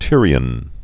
(tĭrē-ən)